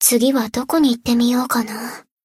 贡献 ） 分类:蔚蓝档案语音 协议:Copyright 您不可以覆盖此文件。
BA_V_Shiroko_Ridingsuit_Cafe_Monolog_3.ogg